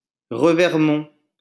Revermont (French pronunciation: [ʁəvɛʁmɔ̃]